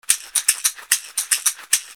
ブルキナファソ製 カシシ
植物繊維の篭、底は瓢箪。小石が入っています。
カシシ大1個